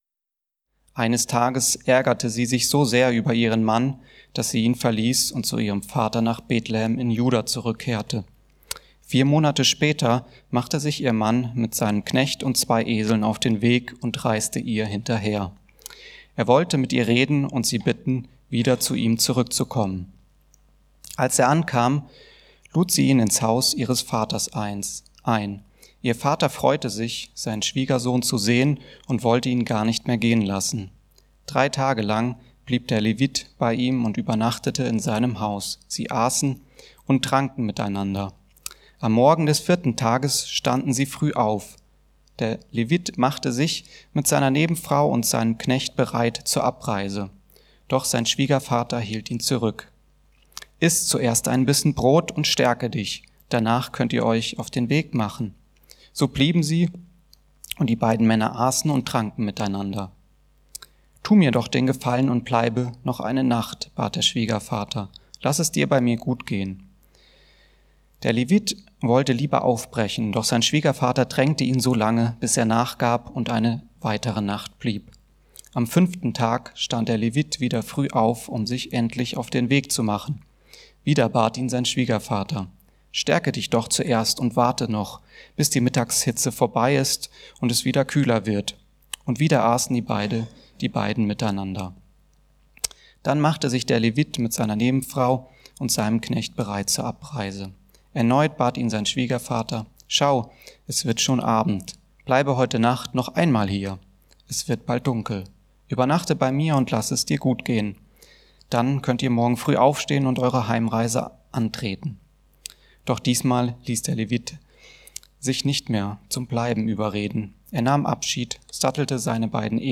Gottes Maßstab Gelten Lassen ~ Mittwochsgottesdienst Podcast